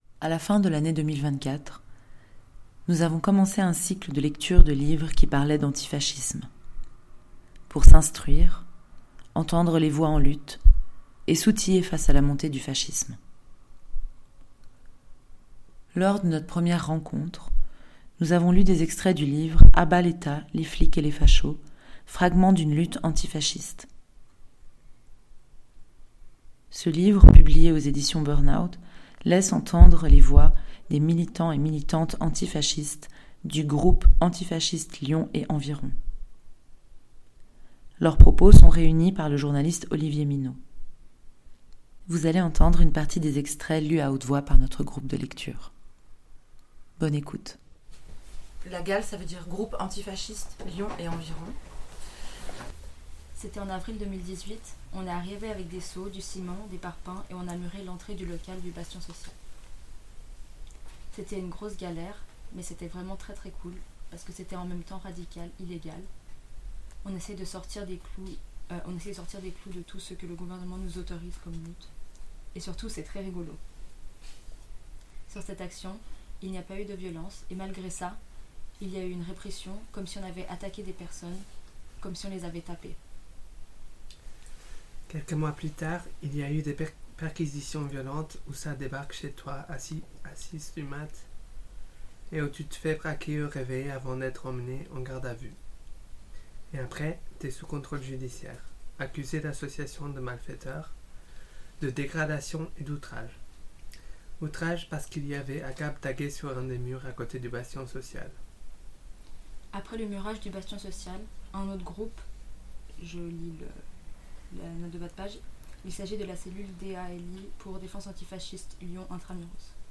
Lecture à voix haute et discussion à partir d'un livre qui aborde l'antifascisme
live_la_gale_2.mp3